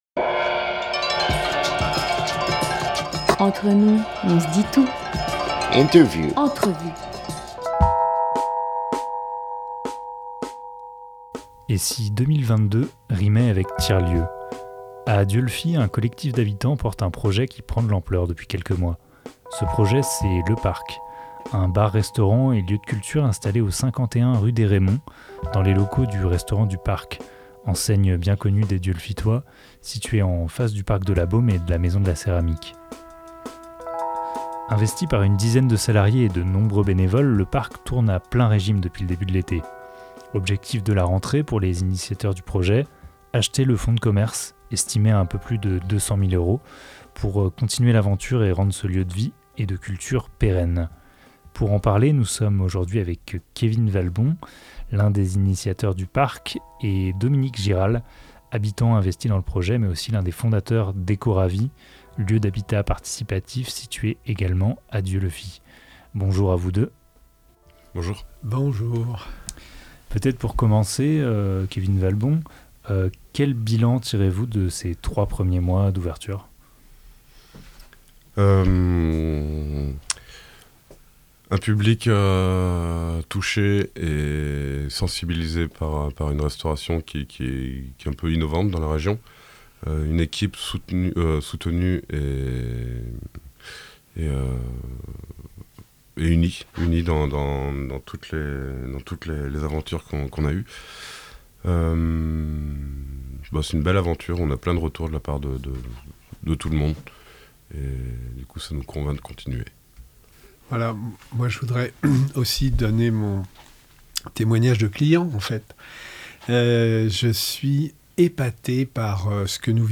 13 septembre 2022 9:36 | Interview